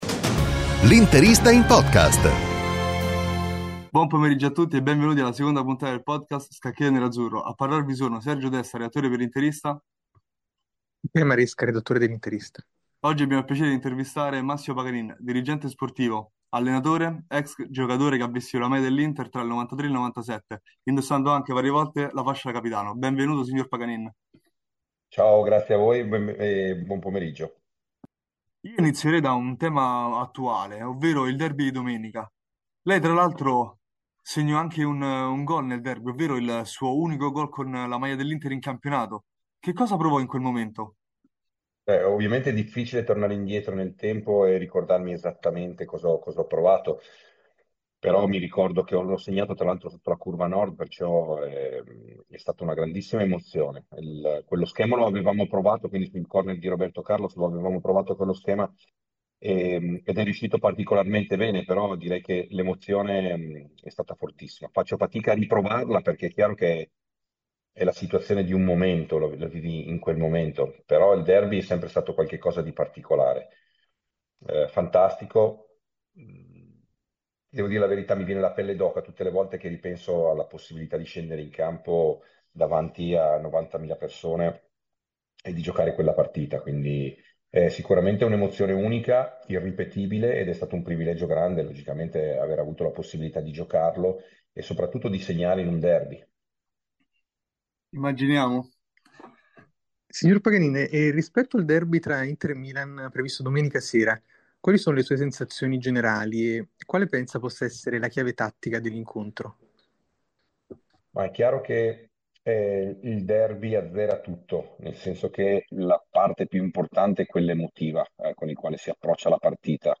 Nella puntata odierna abbiamo il piacere di intervistare Massimo Paganin, Dirigente sportivo, allenatore ed ex allenatore che ha vestito la maglia dell'Inter dalla stagione 93 alla 97. Tra i temi toccati, ovviamente, il più caldo e imminente, ovvero il derby di domenica.